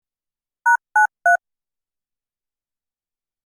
該当の項目をクリックすると、電話のプッシュ音のような音が流れます。